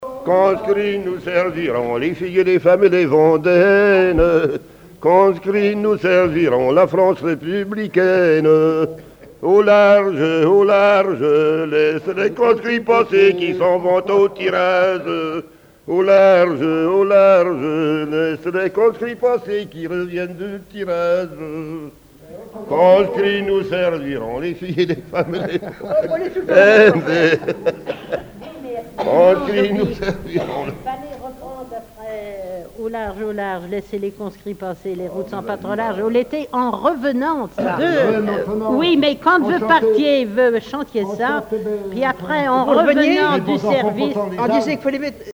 Chants brefs - Conscription
Fonction d'après l'analyste gestuel : à marcher
Regroupement de témoins ; chanteuses, chanteurs, musiciens
Pièce musicale inédite